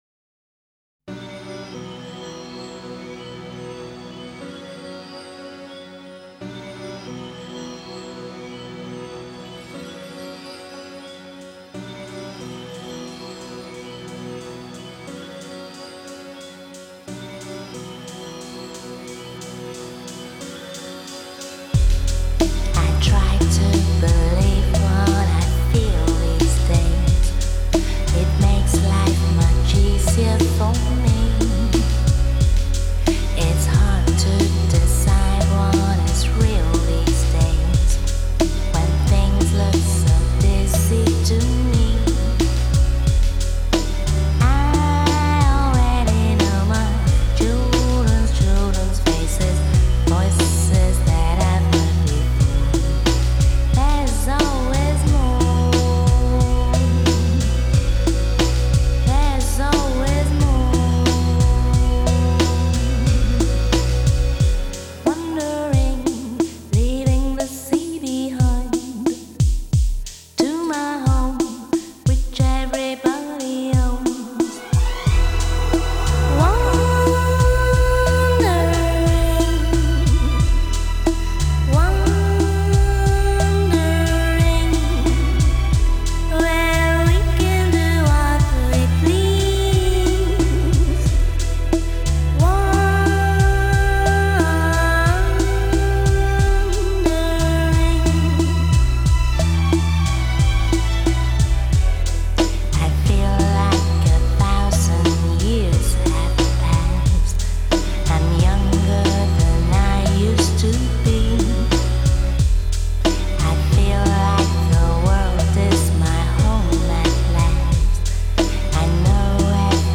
magical vocal